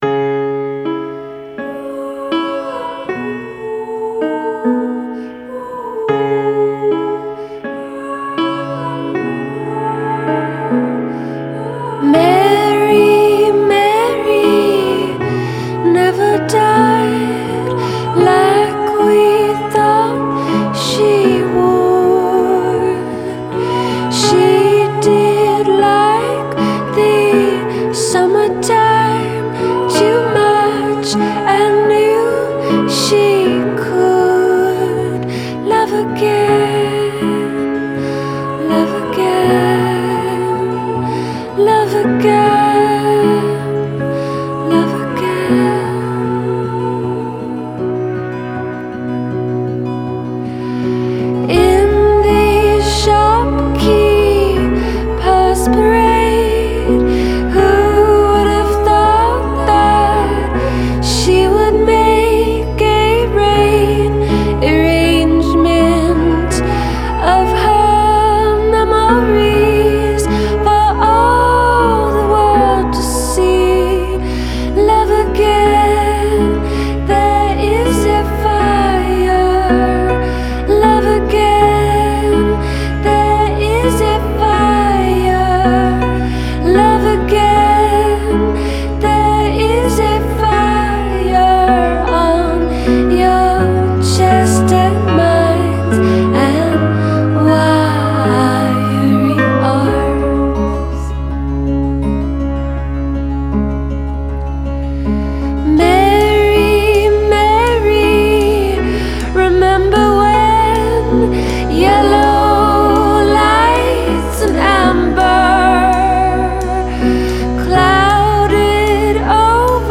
Style: Indie Folk